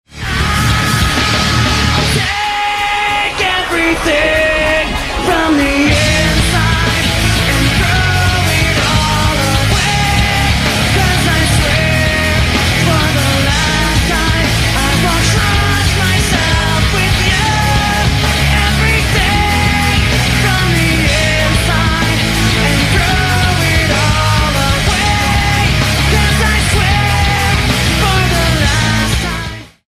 • Comments: Slightly better quality.